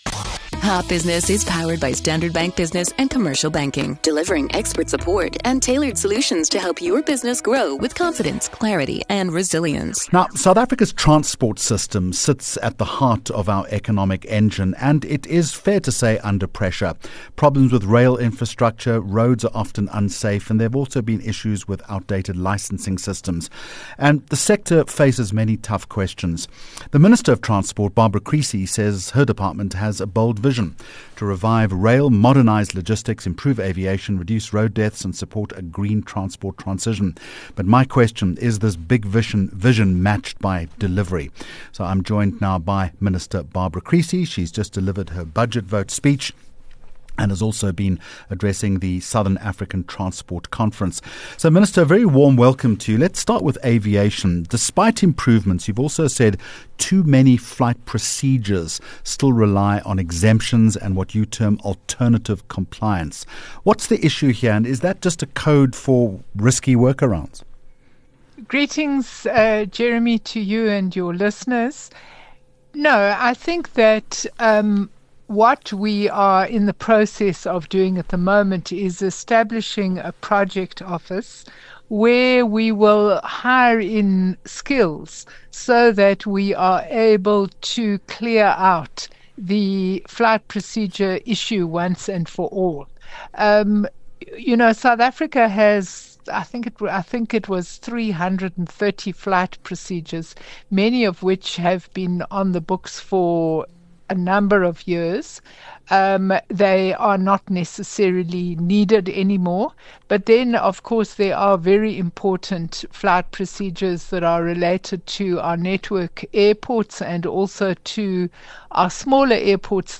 7 Jul Hot Business Interview - Barbara Creecy 07 July 2025
HOT TOPIC Topic: Creecy outlines new plans to improve airport travel in South Africa. Guest: Barbara Creecy -Minister of Transport